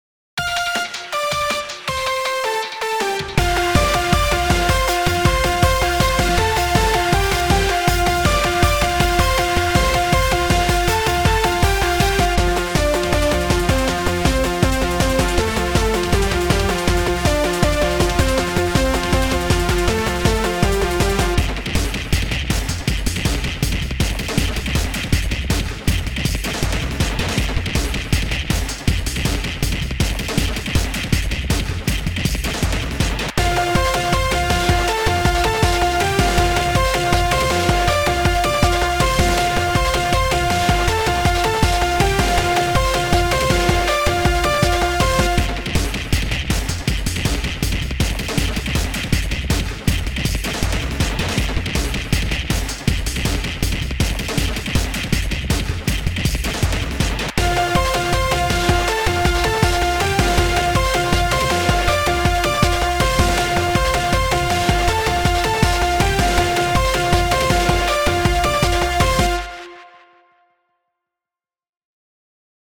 Synth track for arcade and brawl mayhem.